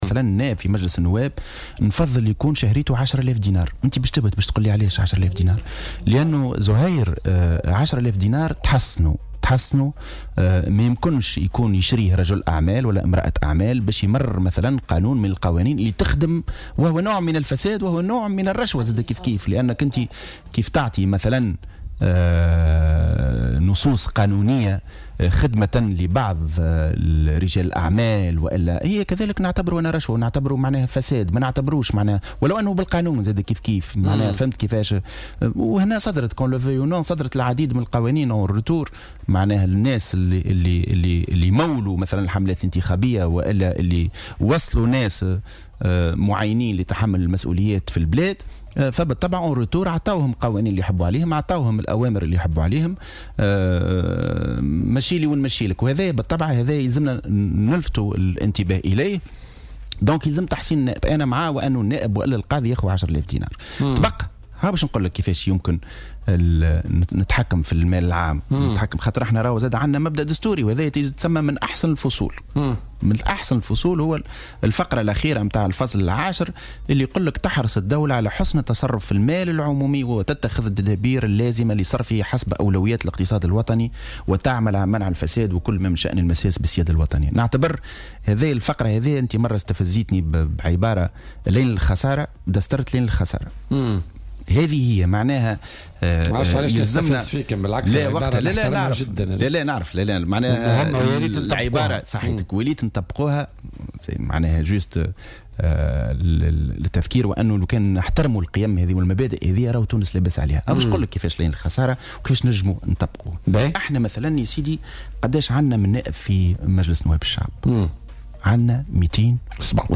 وأوضح في مداخلة له اليوم في برنامج "بوليتيكا" أنه لا بد من الترفيع في أجور النواب مع التقليص من عددهم وتوفير كل الإمكانيات ليعملوا باستقلالية و"لتحصينهم وعدم شراء ذممهم"، وفق تعبيره.